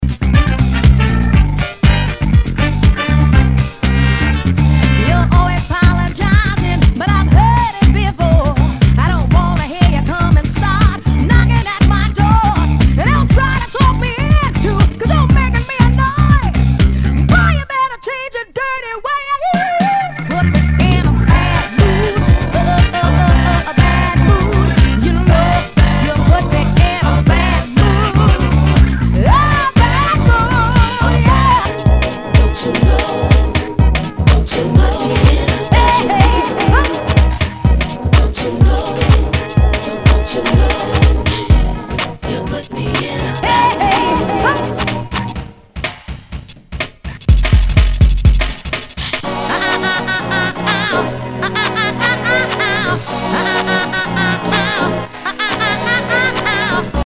HOUSE/TECHNO/ELECTRO
ナイス！ヴォーカル・ハウス・クラシック！